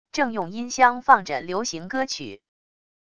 正用音箱放着流行歌曲wav音频